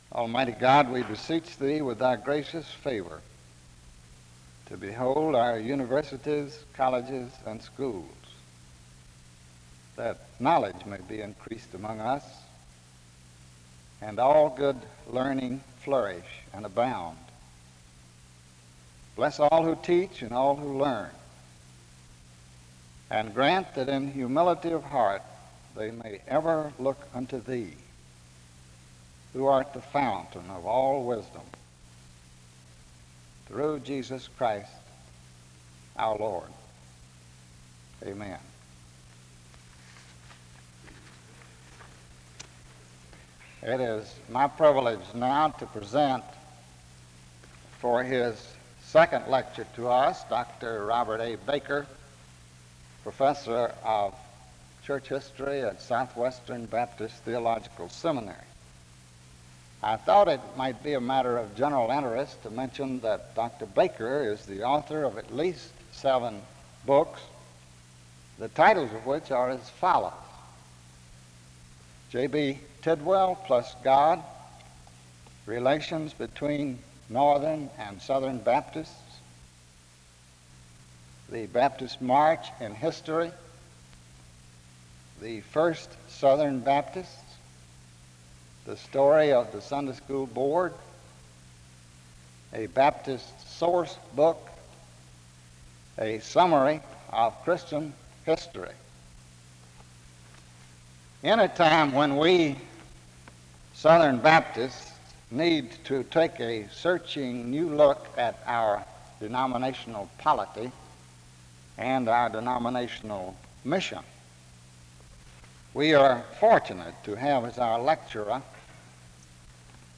The service begins with an opening word of prayer from 0:00-0:38.
A closing prayer is offered from 45:35-46:04. This is part 2 of a 4 part lecture series.
In Collection: SEBTS Chapel and Special Event Recordings